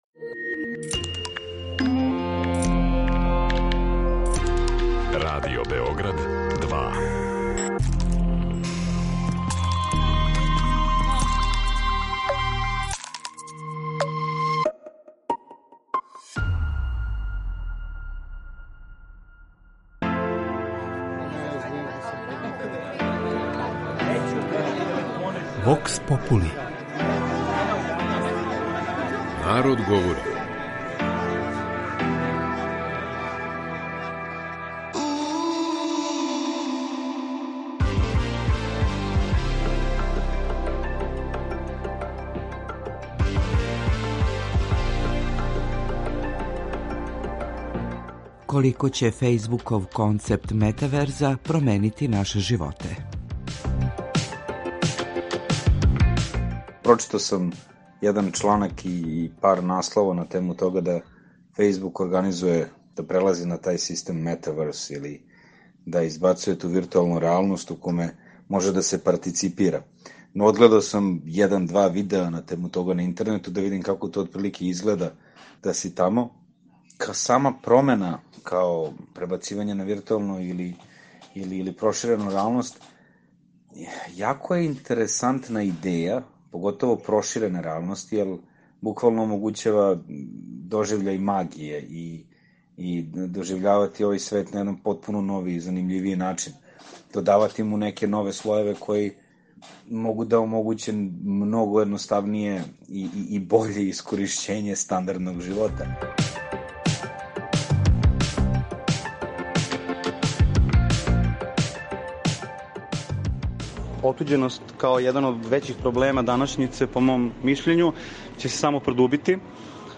кроз кратке монологе, анкете и говорне сегменте у којима ће случајно одабрани, занимљиви саговорници одговарати на питања